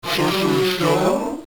(Links throughout this page point to mp3s from the game.)
It was a booming, metallic voice... one that Namor recognized immediately.